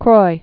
(kroi)